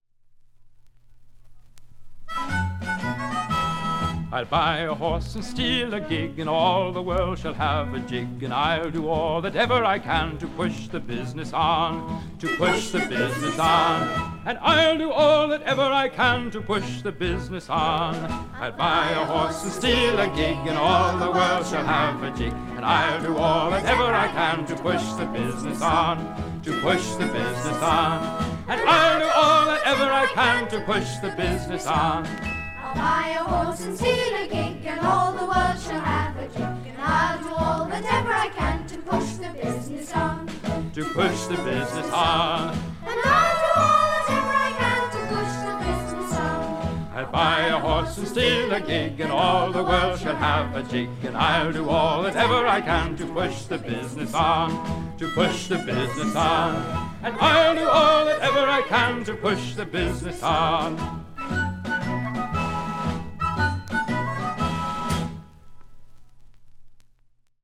englisches Kinderlied